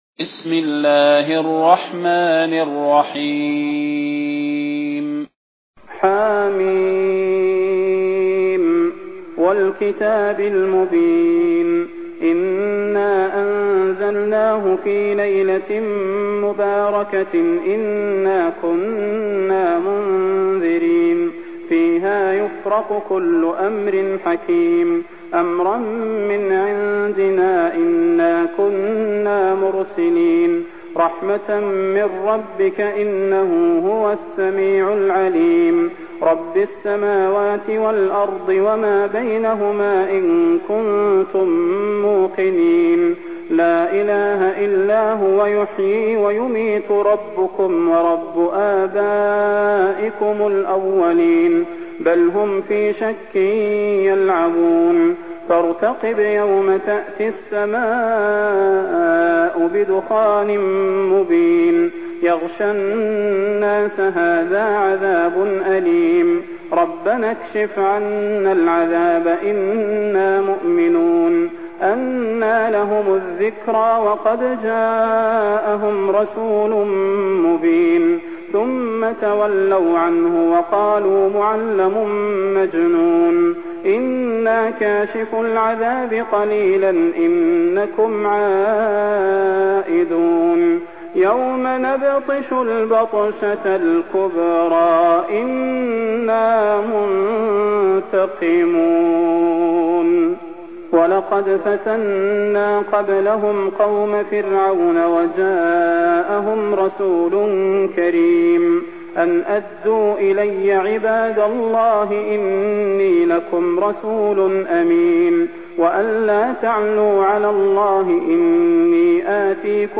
المكان: المسجد النبوي الشيخ: فضيلة الشيخ د. صلاح بن محمد البدير فضيلة الشيخ د. صلاح بن محمد البدير الدخان